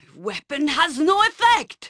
mounting panic that her weapon has no effect.